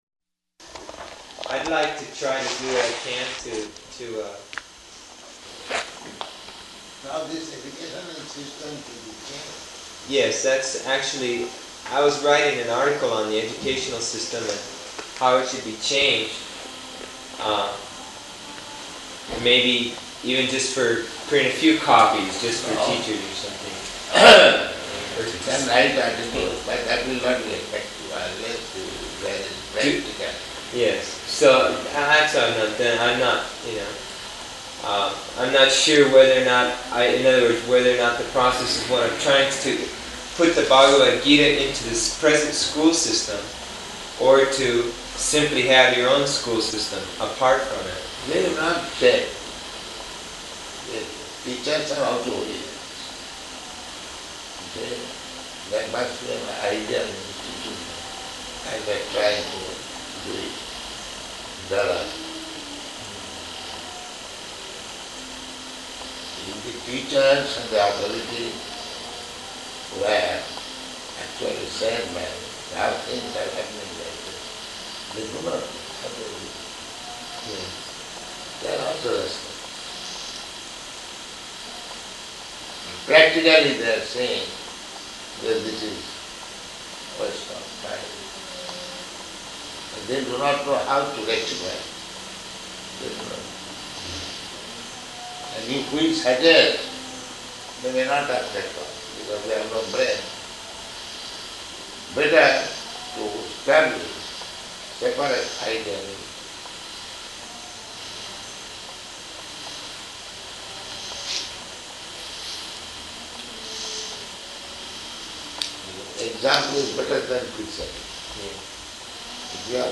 -- Type: Conversation Dated: June 15th 1975 Location: Honolulu Audio file